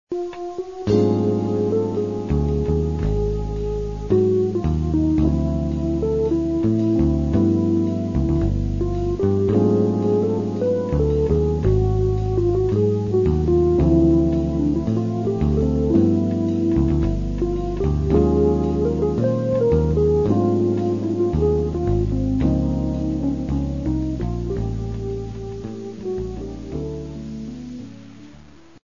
TUNES WITH A BROKEN-UP FEEL